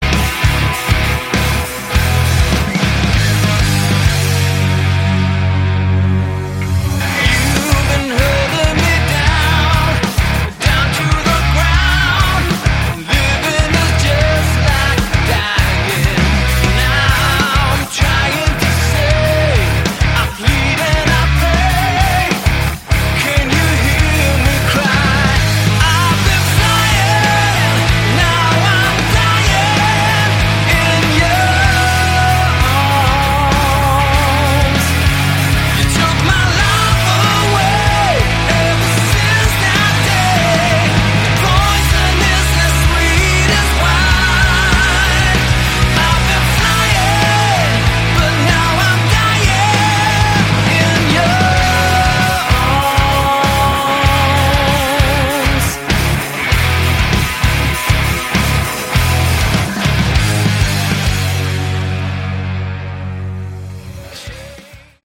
Category: Hard Rock
vocals, guitars
bass
drums, backing vocals